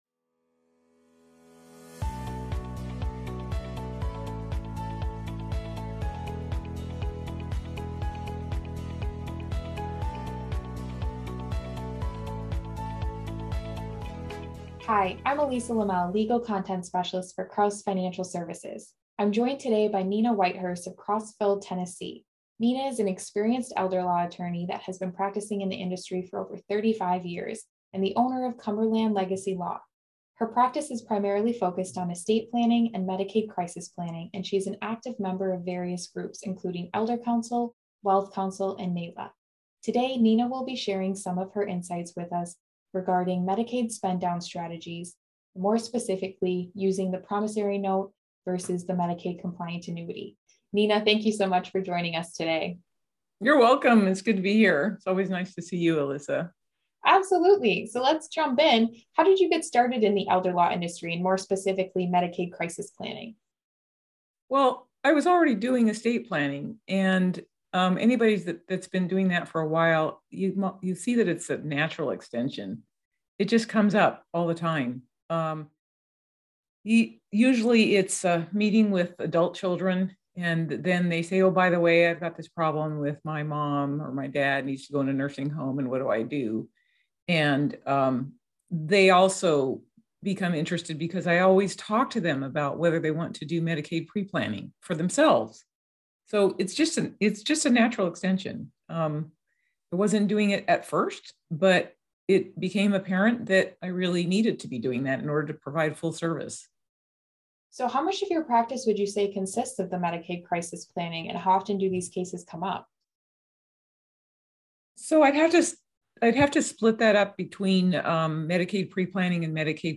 This interview contains important insight for any elder law attorney!